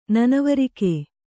♪ nanavarike